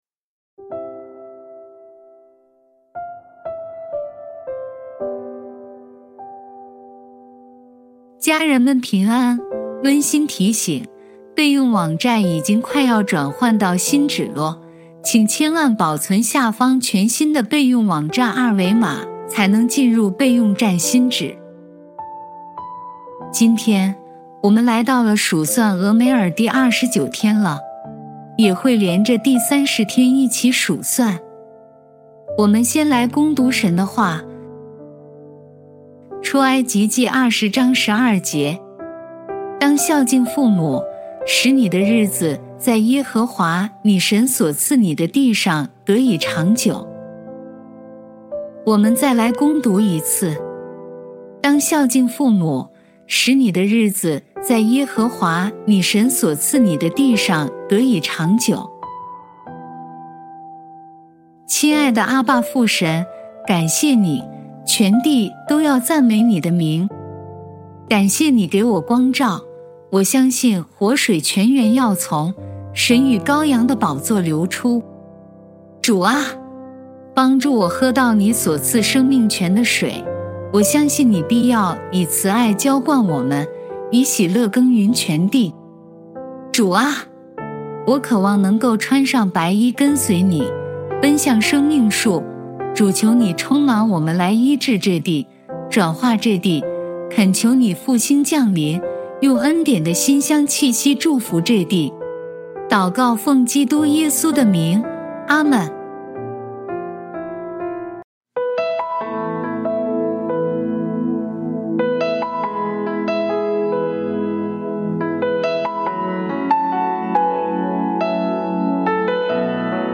数算俄梅珥第29-30天祷告